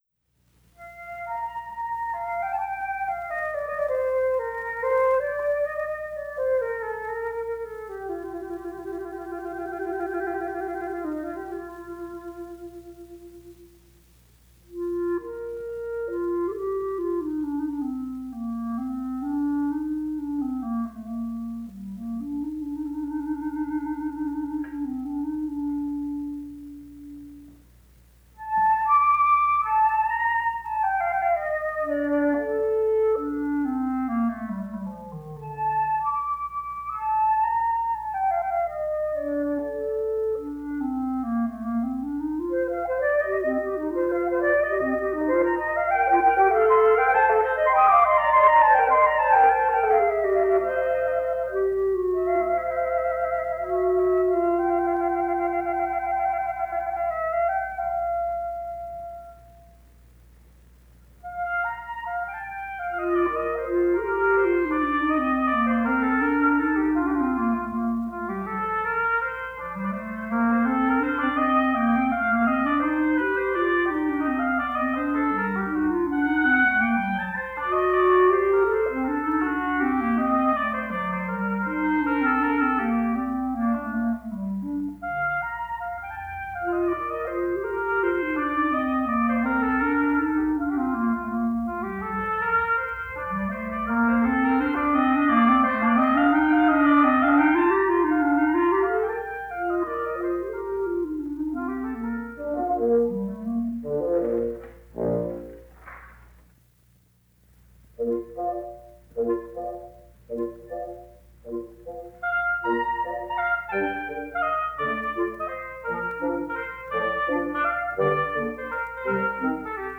The following pieces were performed at the Curtis Institute of Music by various wind ensembles from 1936 to 1941 under the direction of Marcel Tabuteau.
Format: 78 RPM